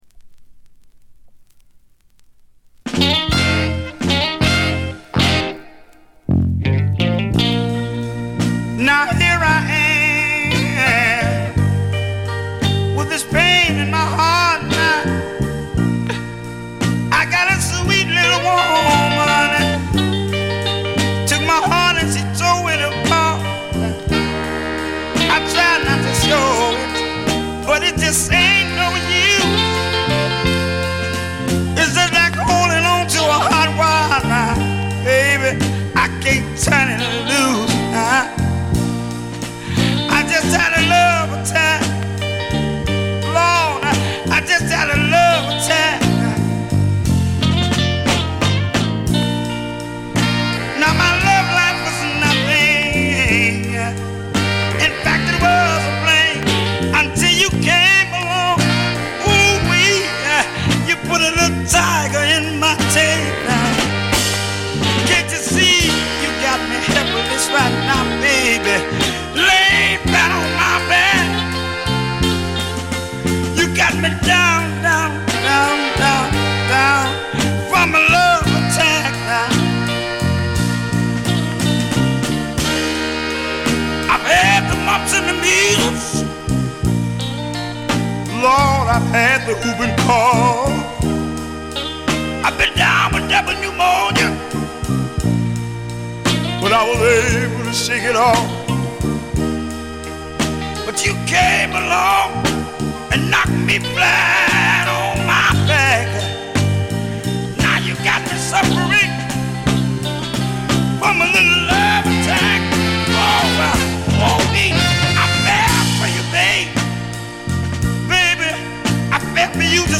モノラル・プレス。
試聴曲は現品からの取り込み音源です。